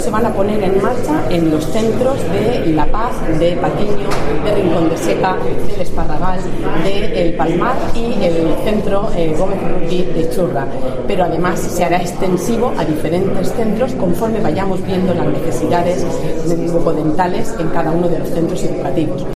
Pilar Torres, concejala de Sanidad del Ayuntamiento de Murcia